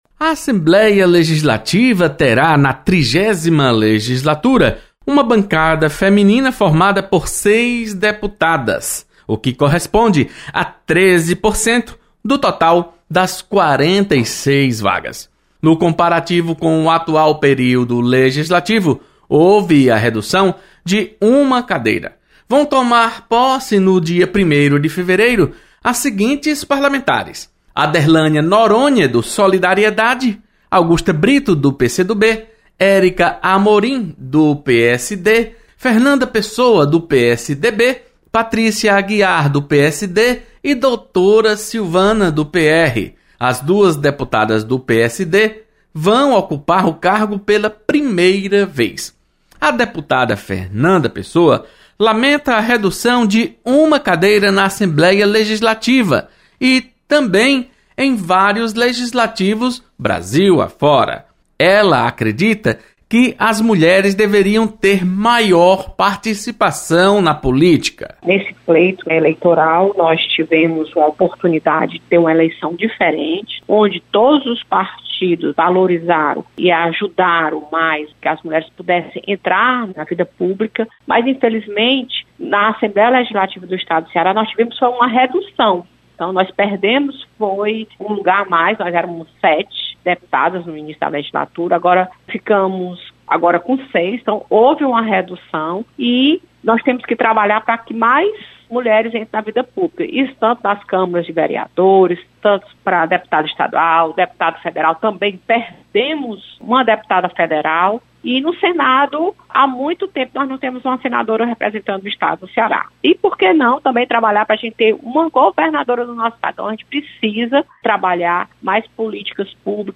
Deputada Fernanda Pessoa enaltece participação das mulheres em chapa que concorre ao comando da Mesa Diretora da Assembleia. Repórter